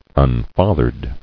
[un·fa·thered]